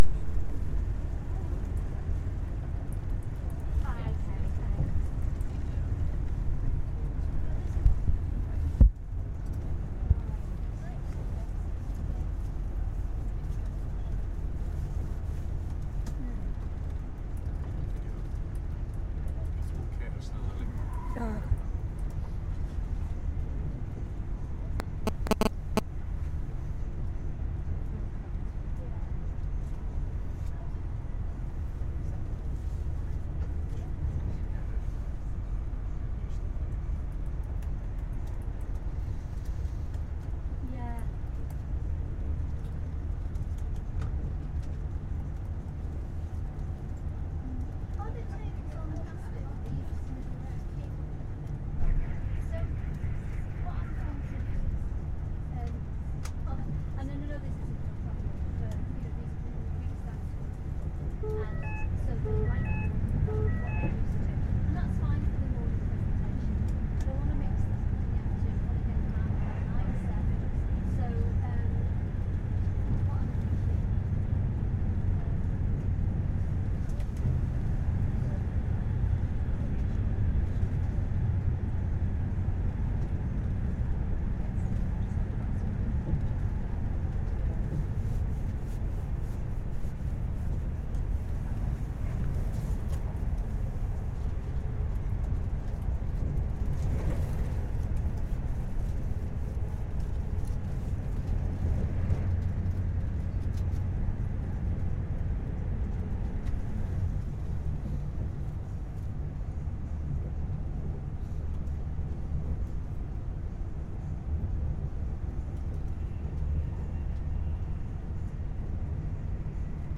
train atmosphere